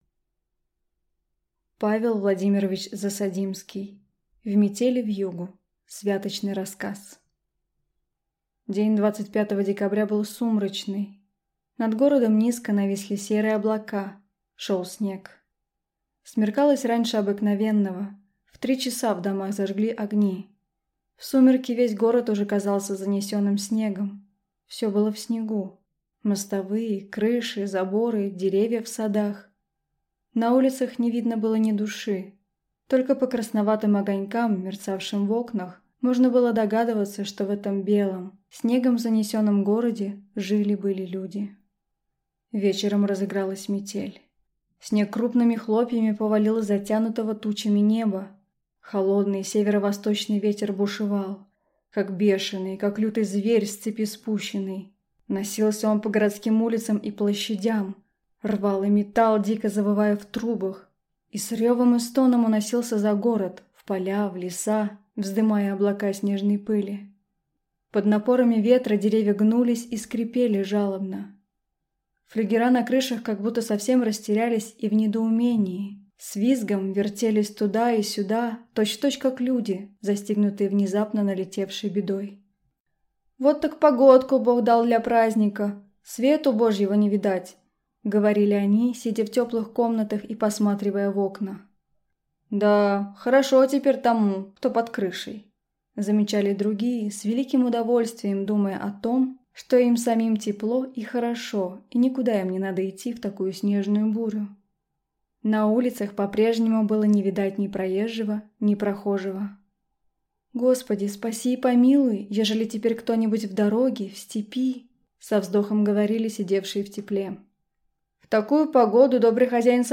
Аудиокнига В метель и вьюгу | Библиотека аудиокниг